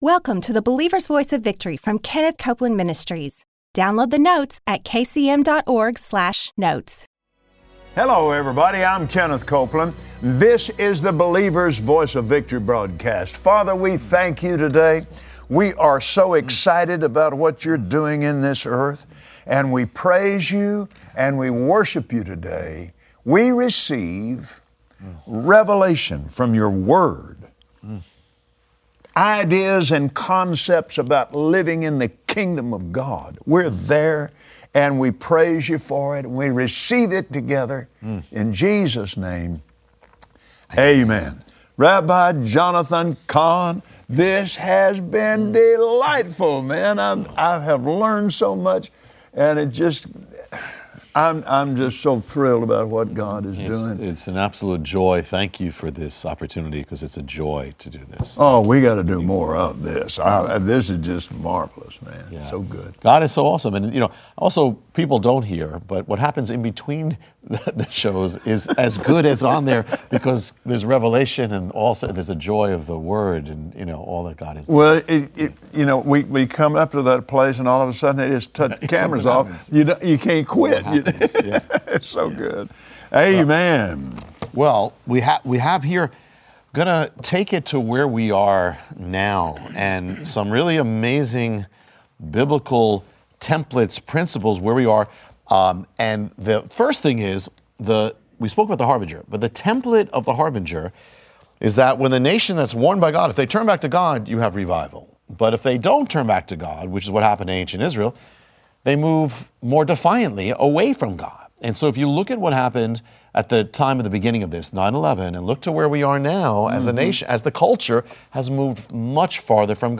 Today, Kenneth Copeland and Rabbi Jonathan Cahn talk about the dangers of any nation that turns away from God, forsaking His principles. It is time for the body of Christ to rise up and take their place in Him.